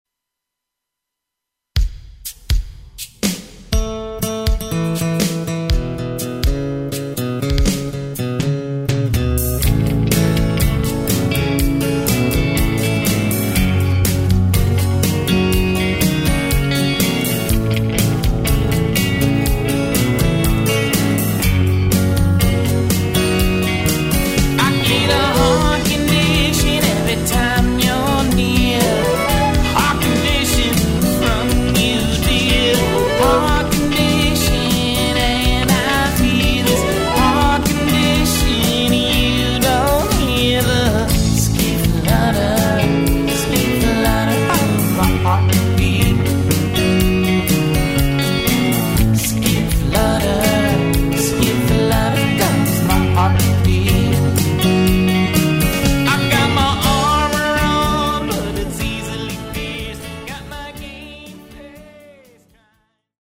lead & harmony vocals
drum programming
violin
Neo-Americana music